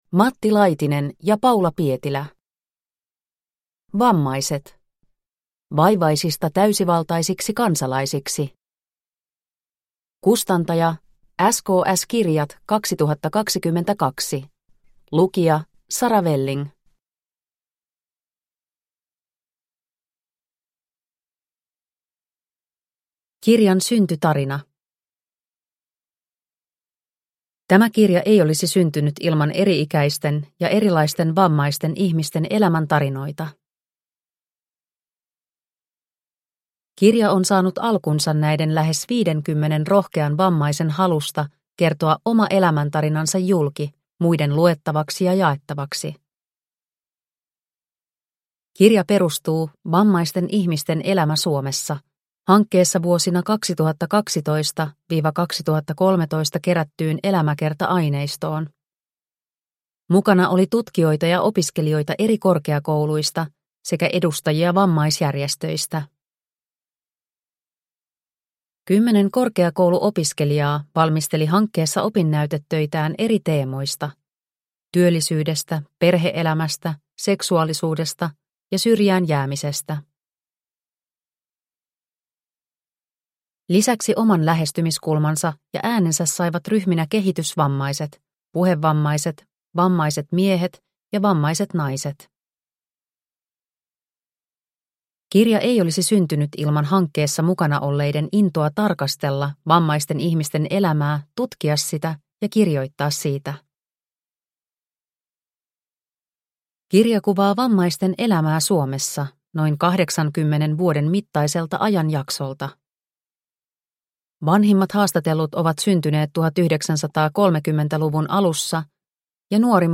Vammaiset – Ljudbok – Laddas ner